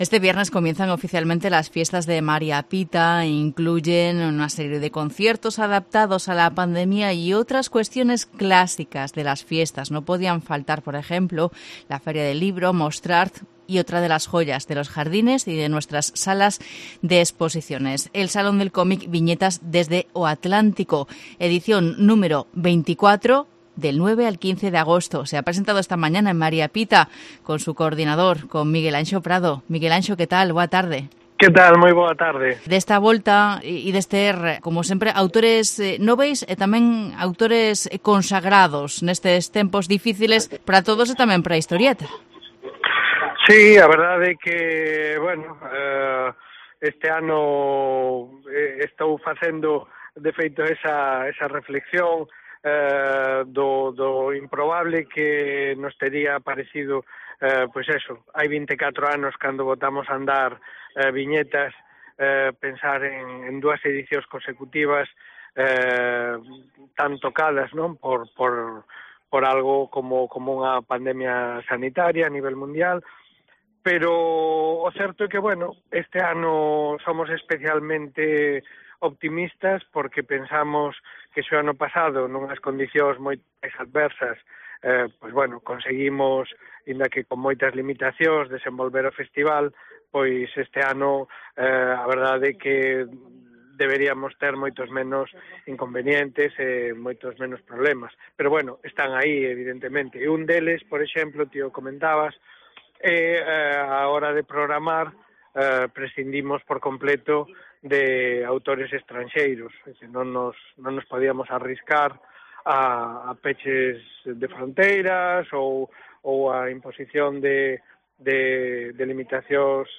Entrevista a Miguelanxo Prado sobre la XXIV Edición del Salón del Comic Viñetas desde O ATtántico